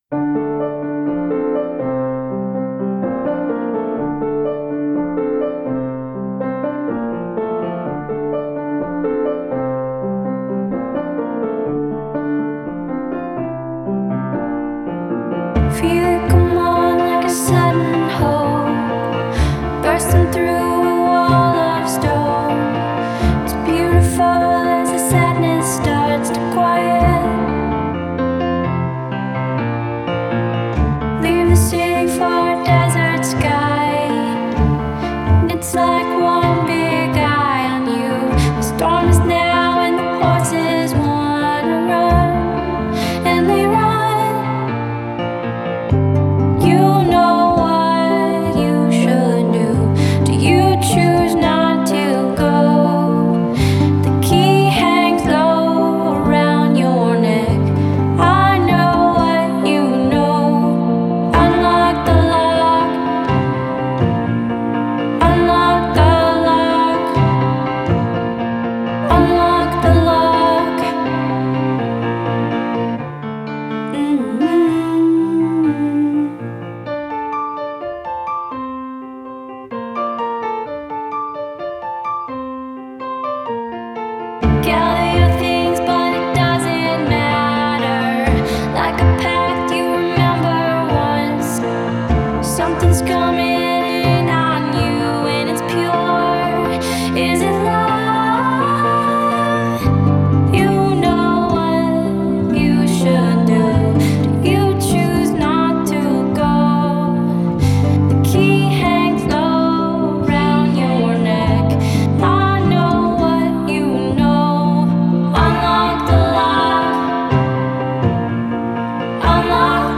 Genre: Indie Pop, Pop Folk, Singer-Songwriter